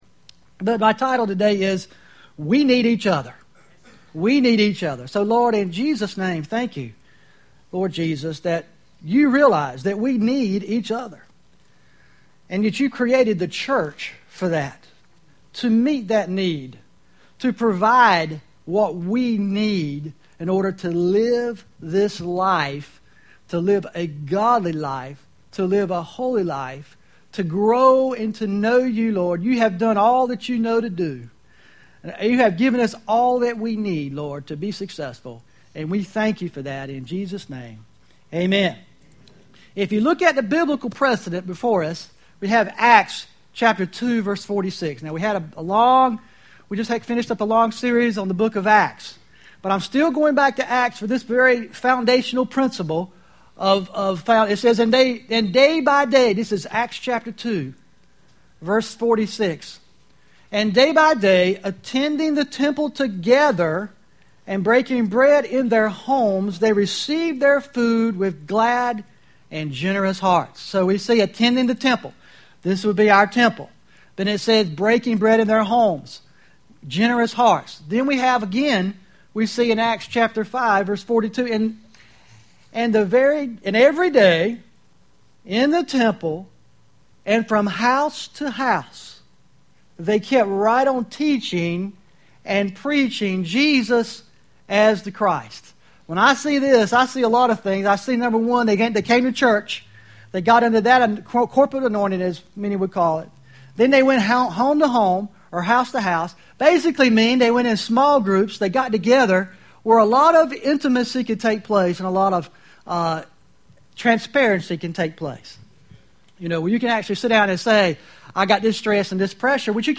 Service Type: Sunday 10am